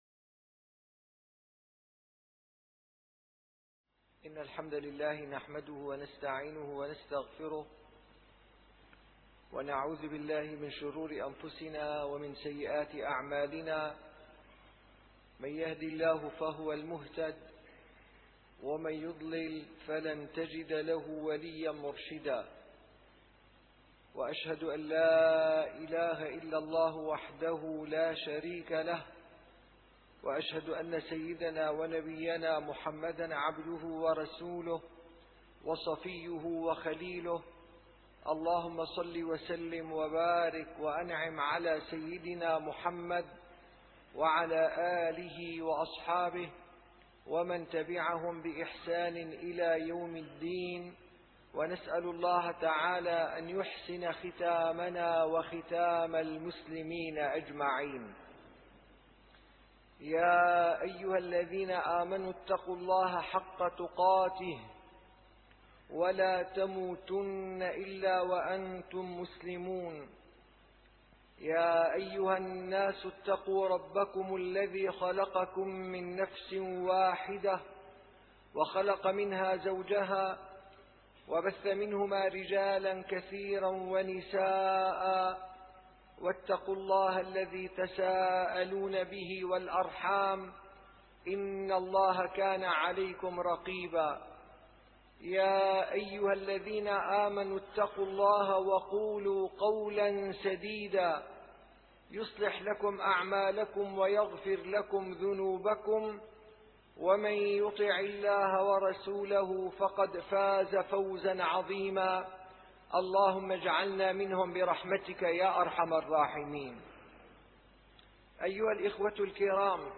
- الخطب - رجال صدقوا ما عاهدوا الله عليه